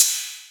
VEC3 Cymbals Ride 20.wav